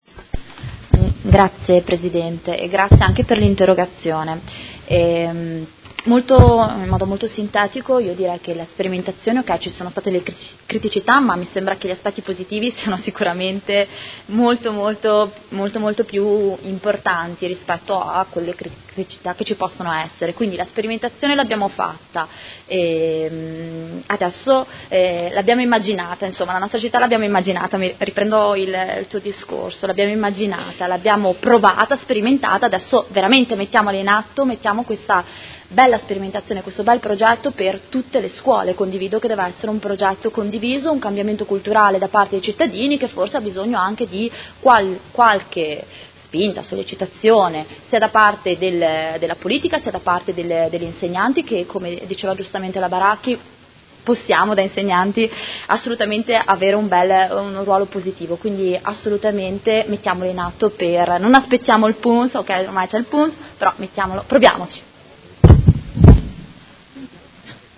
Seduta del 17/01/2019 Dibattito. Interrogazione dei Consiglieri Carpentieri e Baracchi (PD) avente per oggetto: Viabilità in Via Frescobaldi – accesso al Polo scolastico.